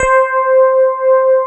Index of /90_sSampleCDs/USB Soundscan vol.09 - Keyboards Old School [AKAI] 1CD/Partition A/13-FM ELP 1